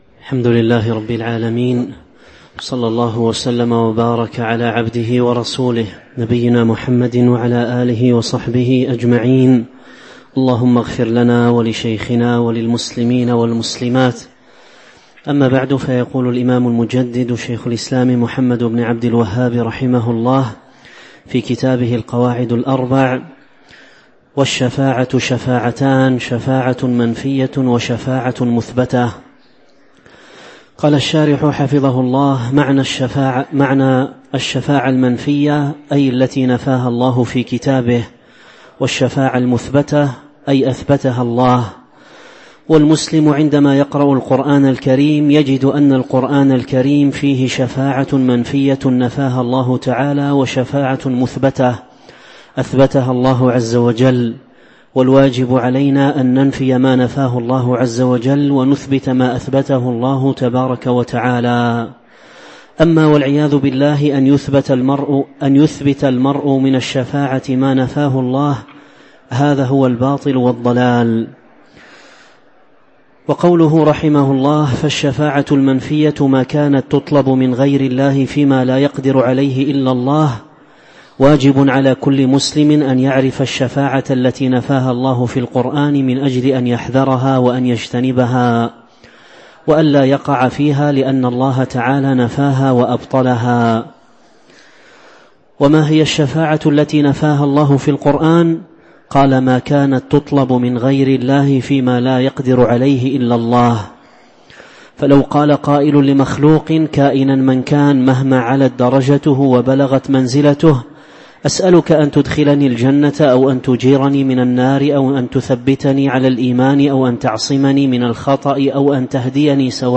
تاريخ النشر ٢٦ شوال ١٤٤٤ هـ المكان: المسجد النبوي الشيخ